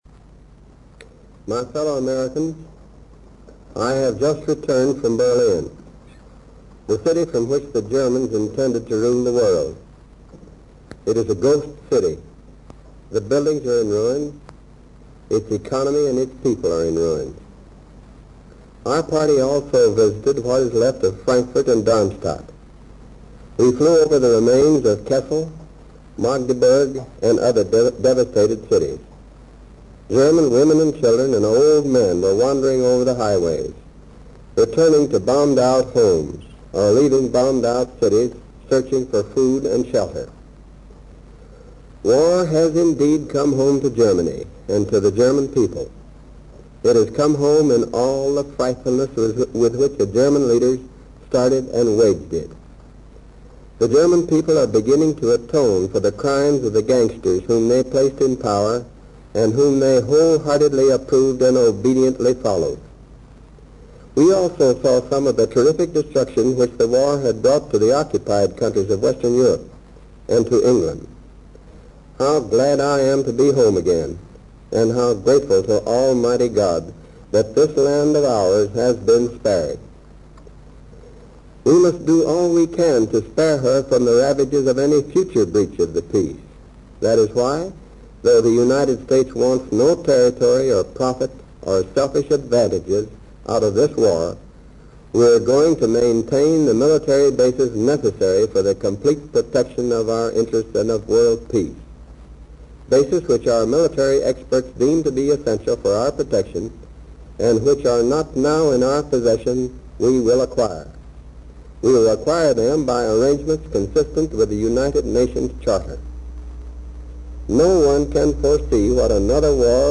On returning from Berlin, President Harry Truman addresses the American people. He touches mostly upon issues of the Potsdam agreement, including the goals for a Germany controlled by the Allies, German reparations and the agreement on Poland. The President also justifies the dropping of the atomic bomb on Hiroshima and warns of things to come if Japan does not surrender.
Boradcast on NBC, Aug. 9, 1945.